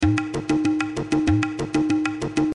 Tag: 96 bpm Ethnic Loops Tabla Loops 434.67 KB wav Key : Unknown